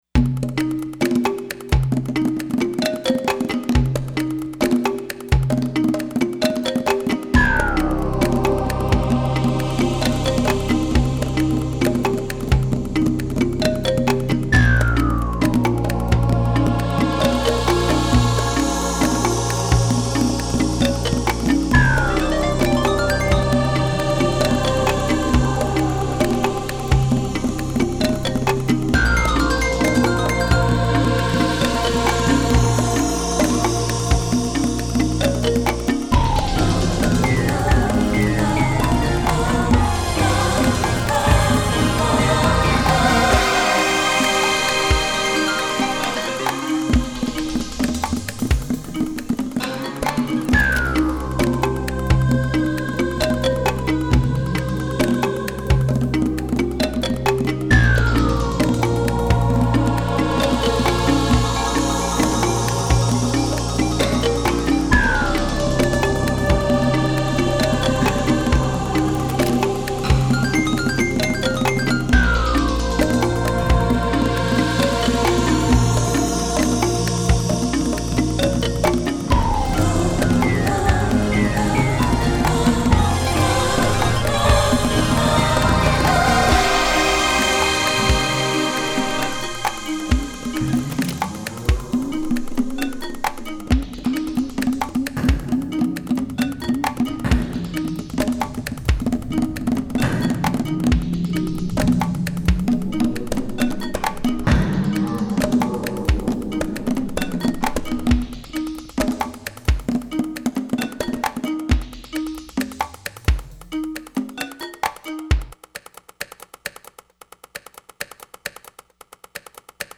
- industrial soundtrack